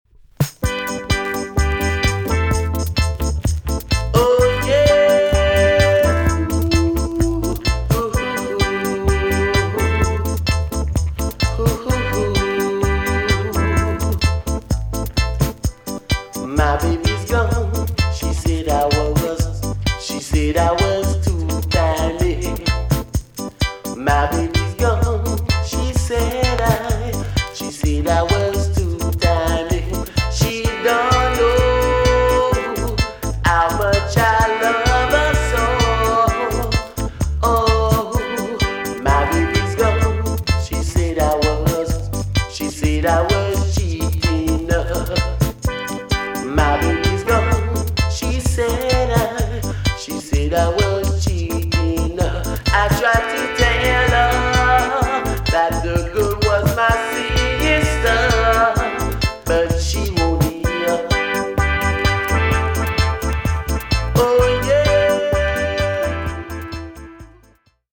TOP >LOVERS >12 inch , DISCO45
EX- 音はキレイです。